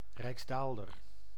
The rijksdaalder (Dutch pronunciation: [rɛiksˈdaːldər]
Nl-rijksdaalder.ogg.mp3